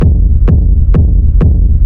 • Techno Sub Kick Black.wav
Techno_Sub_Kick_Black_1__Oj6.wav